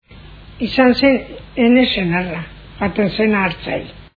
Dialectos
Salacenco